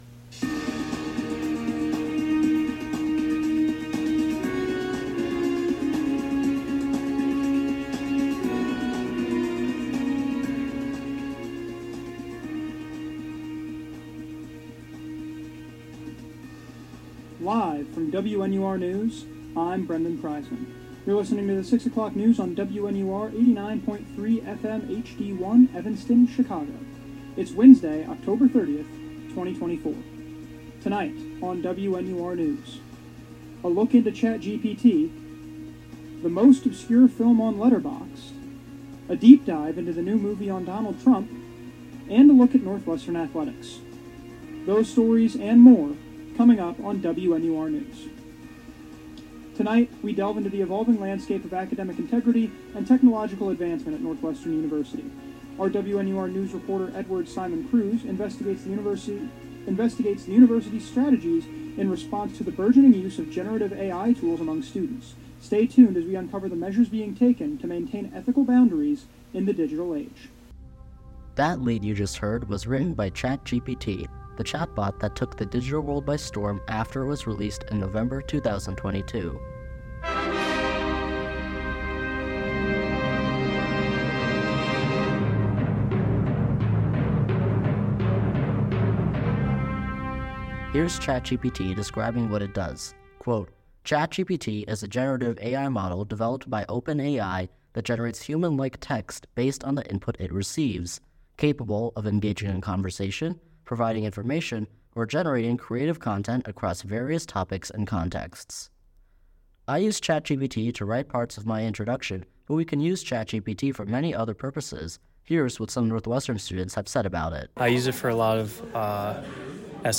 October 30, 2024: AI at Northwestern, a movie without Letterboxd reviews, and the mystery of Spirit Halloween. WNUR News broadcasts live at 6 pm CST on Mondays, Wednesdays, and Fridays on WNUR 89.3 FM.